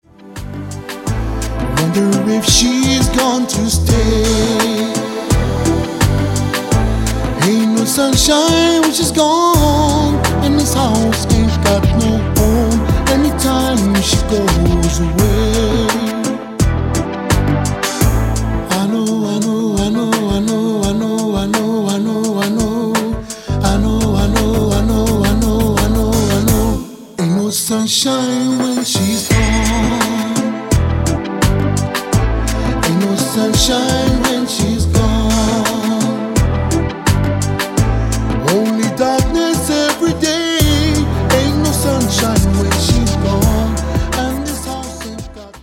• Genre: Contemporary Hawaiian.
guitar